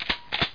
shtgun2.mp3